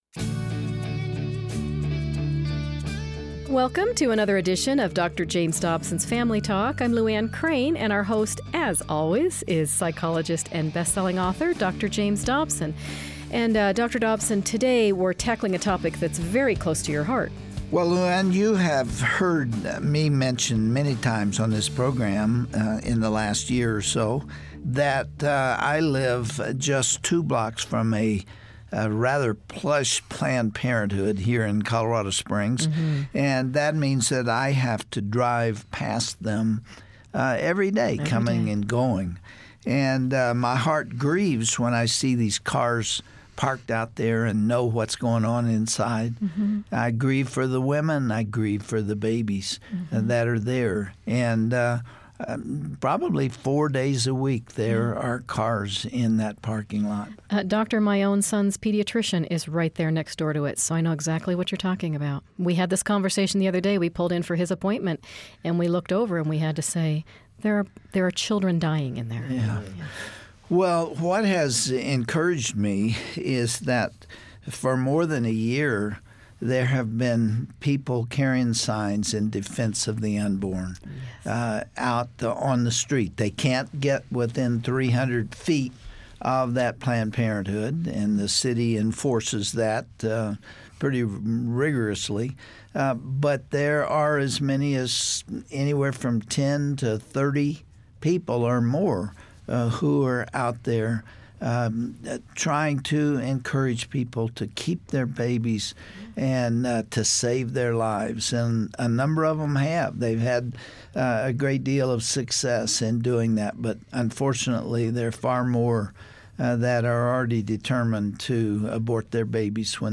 If so, be sure to catch this program as you will hear from some pro-life activists at the local level who can tell you HOW.